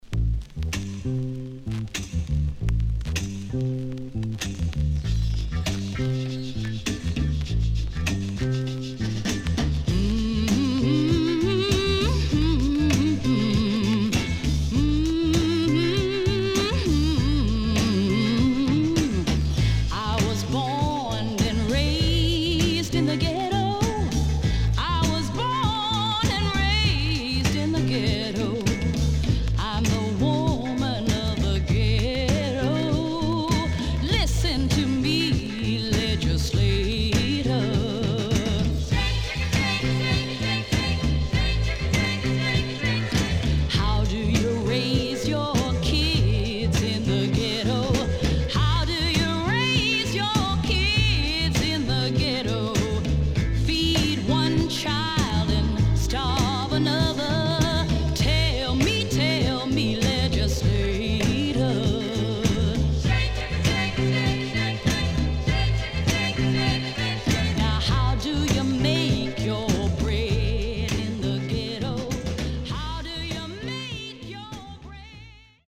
HOME > SOUL / OTHERS
SIDE A:少しチリノイズ入りますが良好です。